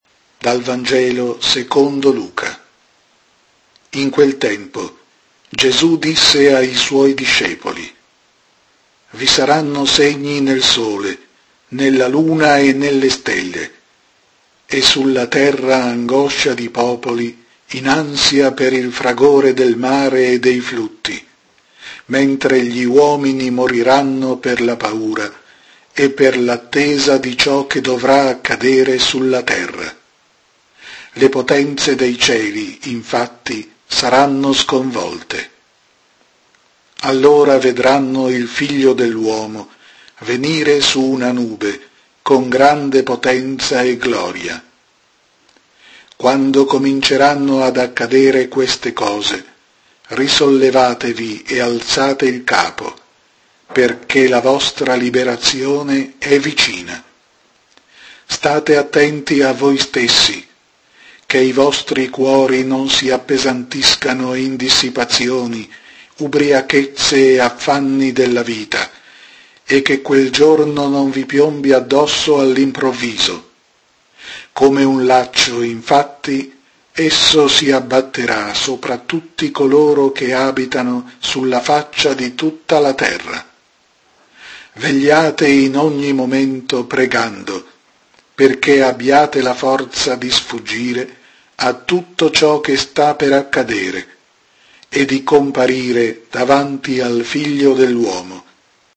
Vangelo